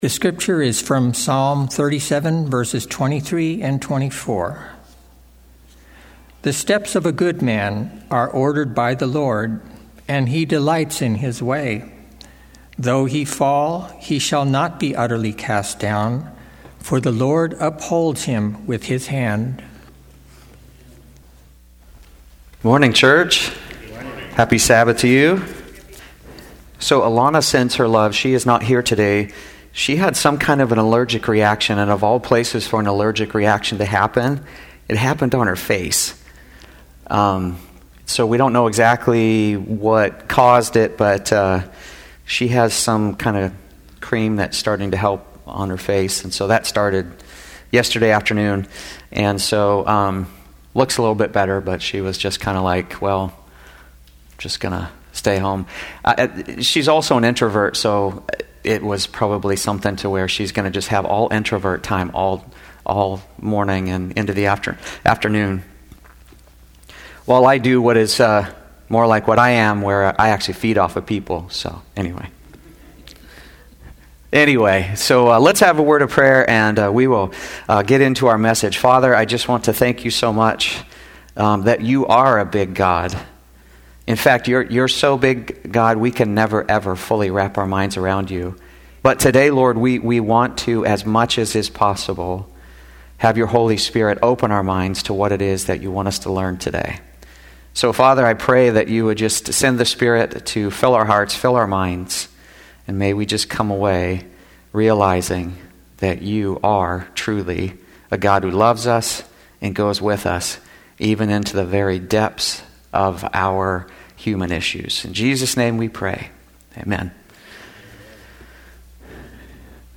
Sermons and Talks 2026